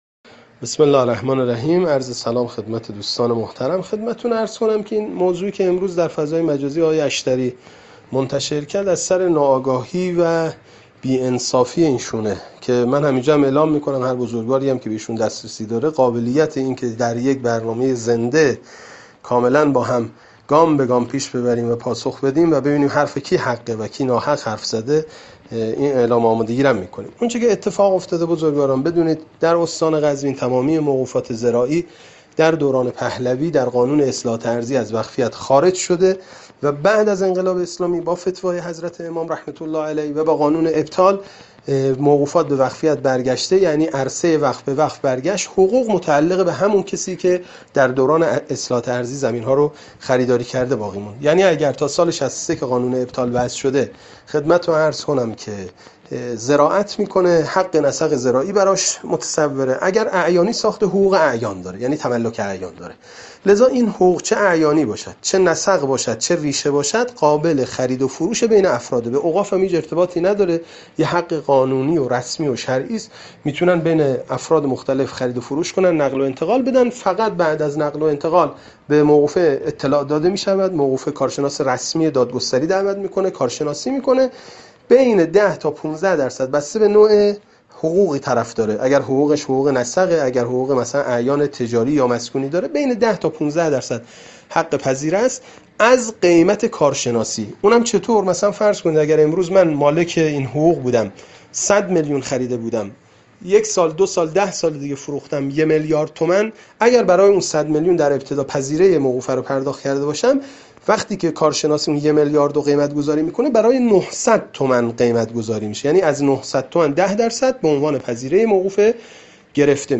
توضیحات حجت‌الاسلام‌والمسلمین سیدمصطفی مجیدی مدیرکل اوقاف و امورخیریه استان قزوین